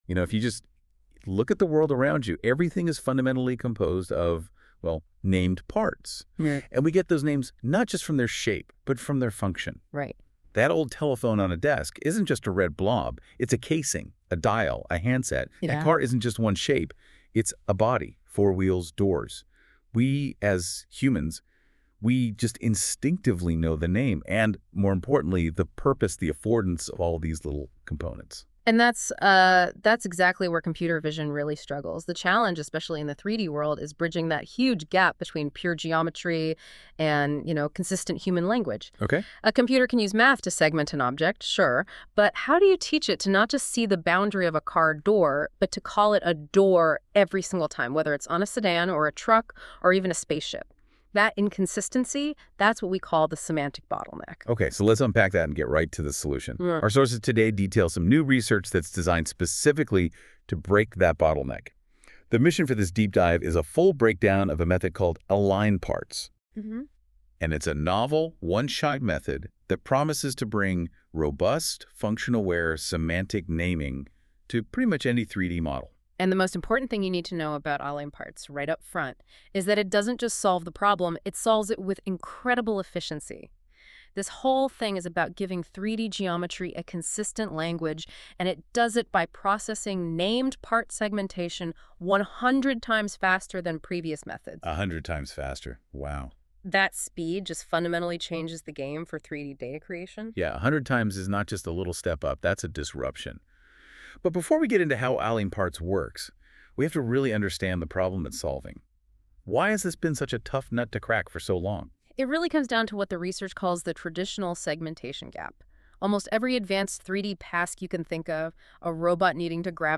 Podcast Cool LLM podcast generated by NotebookLM .